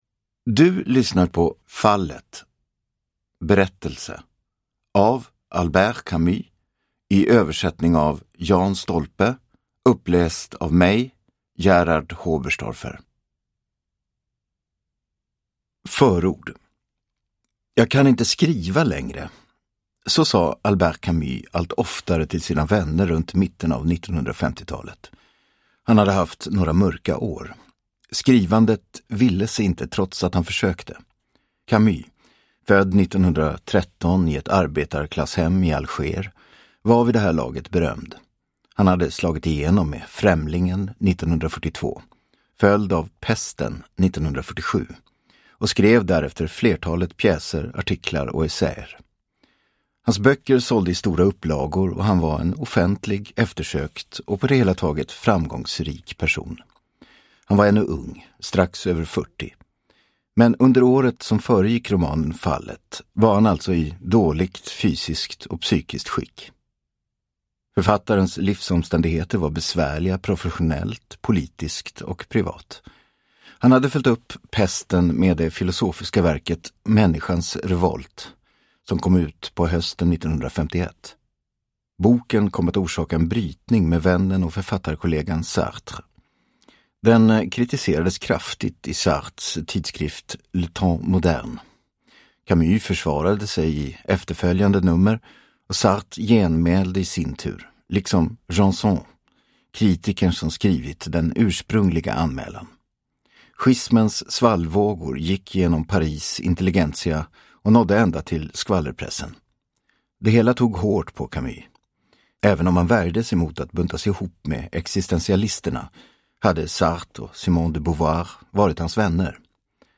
Fallet – Ljudbok – Laddas ner